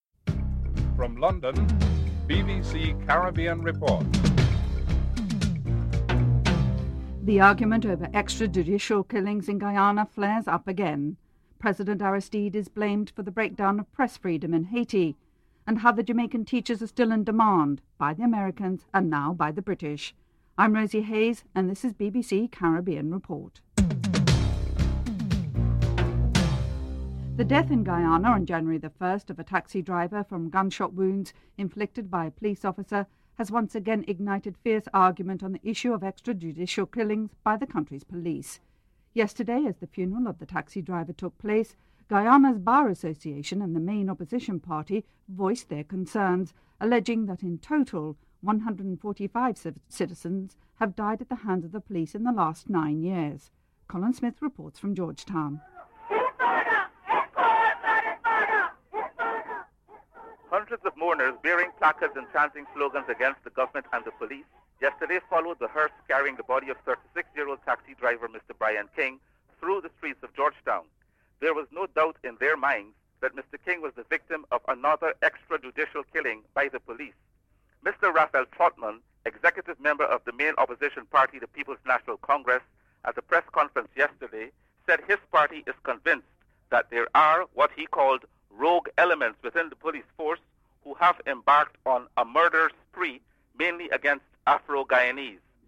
1. Headlines (00:00-00:25)